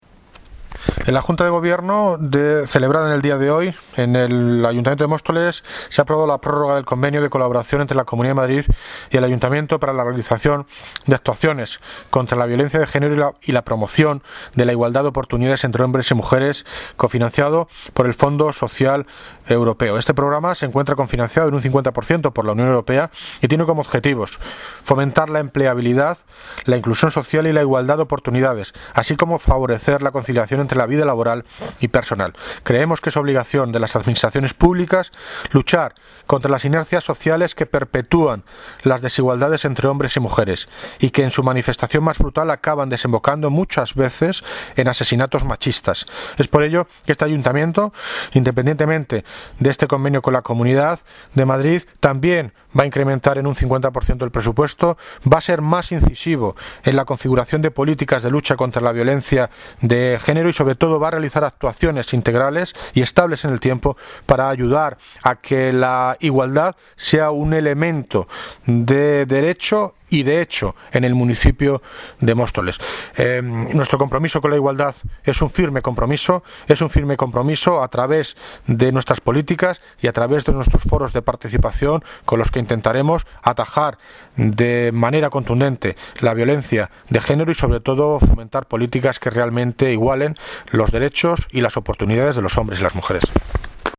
Audio - David Lucas (Alcalde de Móstoles) Sobre convenio Comunidad Ayto Igualdad